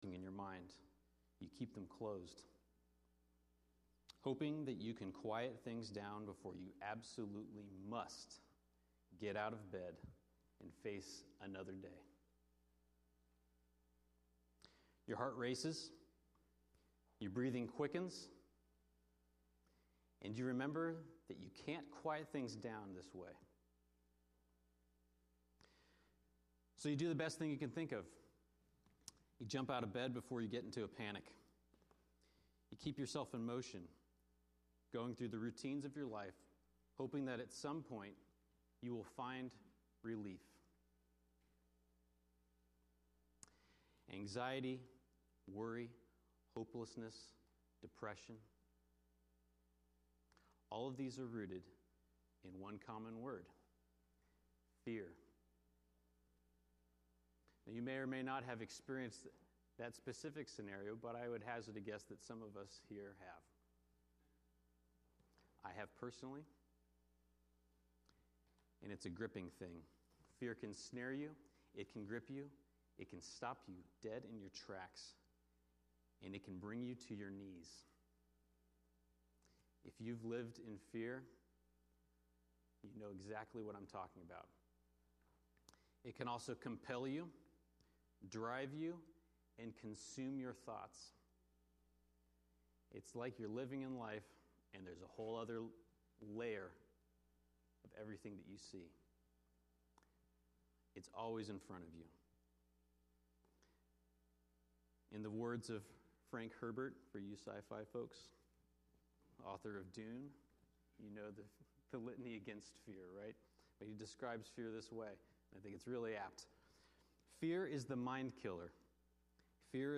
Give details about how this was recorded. That song was removed from this video for copyright reasons.